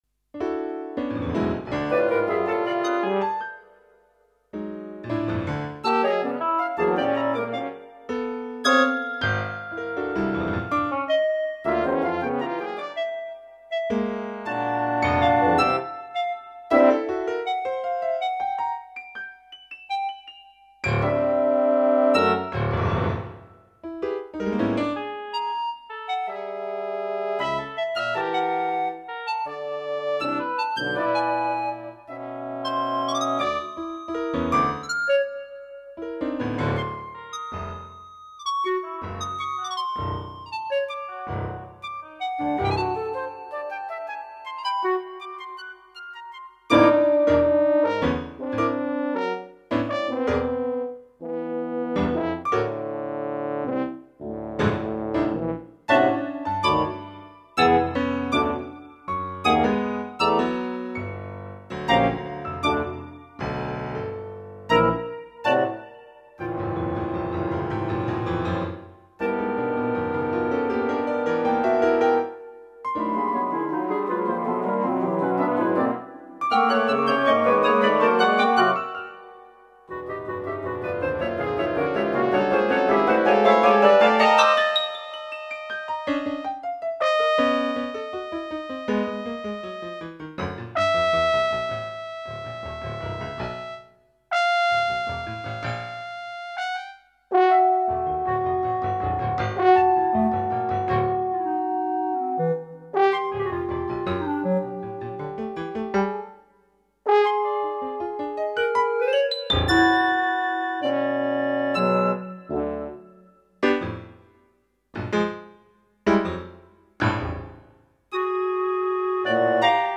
audio 44kz stereo